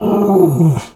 bear_pain_hurt_02.wav